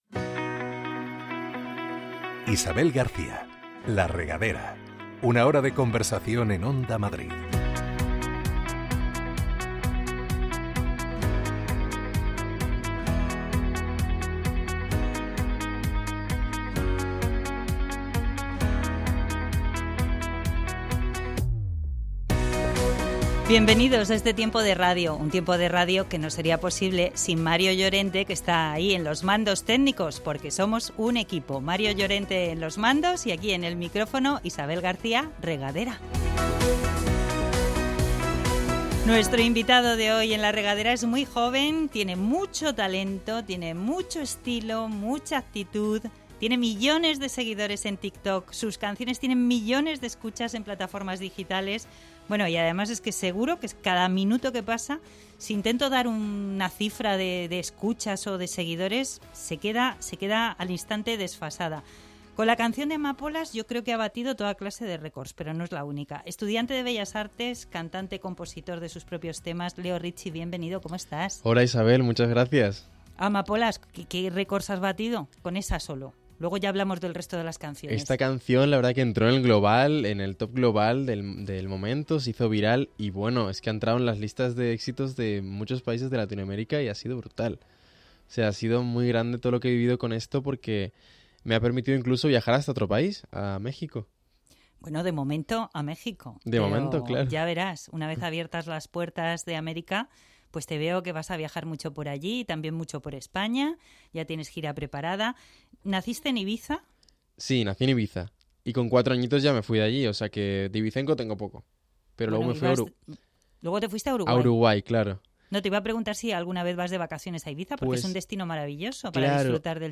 Un espacio para conversar, con buena música de fondo y conocer en profundidad a todo tipo de personajes interesantes y populares.